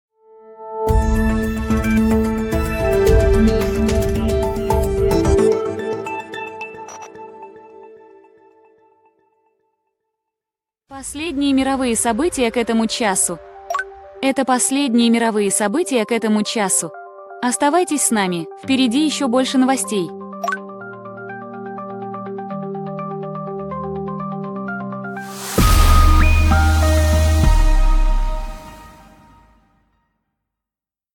После этого новости сохраняются в базу и проходят синтез речи. Далее, несколько раз в сутки, формируются готовые пользовательские аудиоблоки - с интро, аутро и музыкальным фоном.